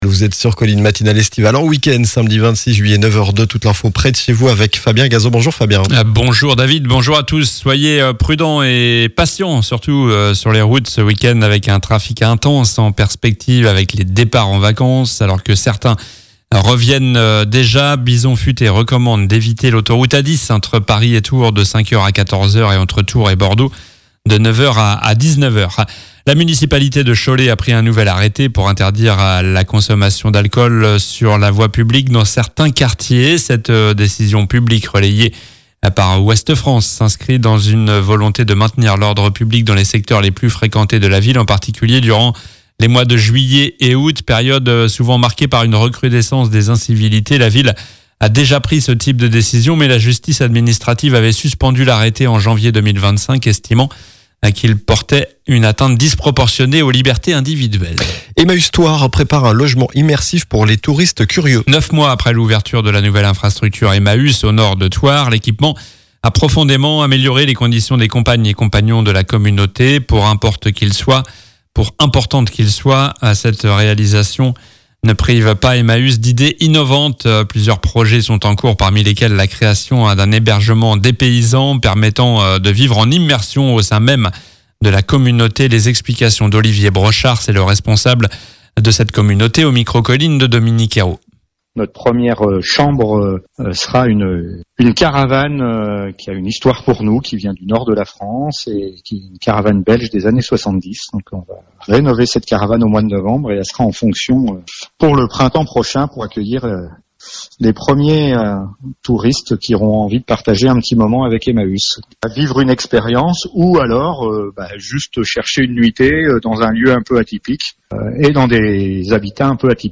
Journal du samedi 26 juillet (matin)